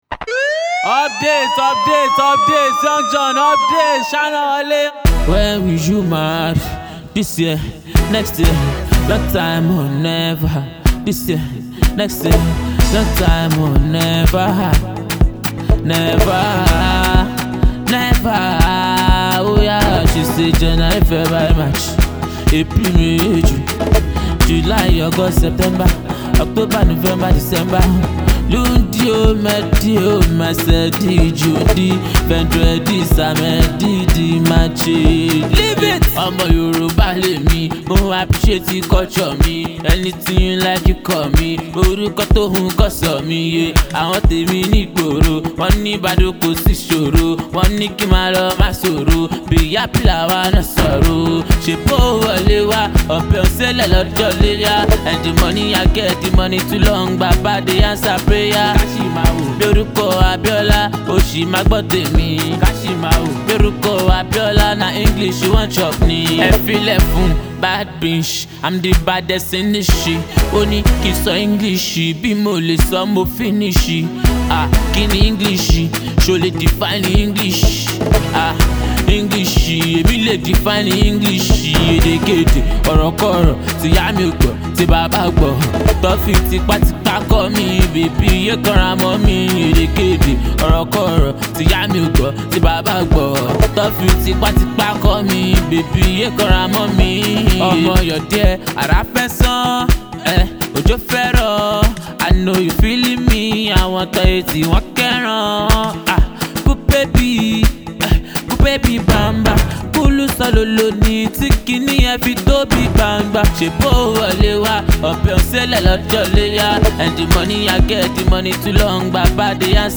street banger